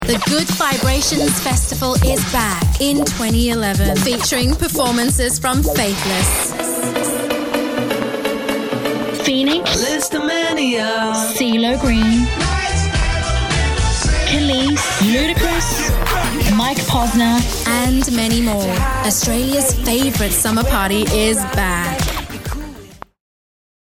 Female
I am a Sydney Australia based singer voice over artist with a natural Australian accent.
Music Promos